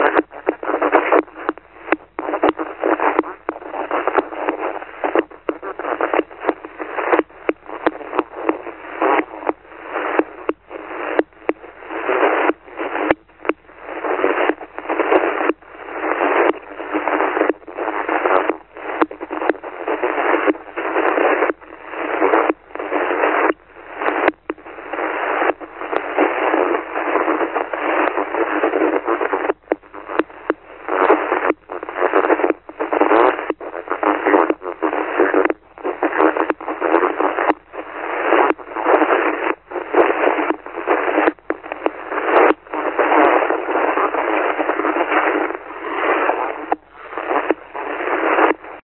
HPA_idle_13MHz.mp3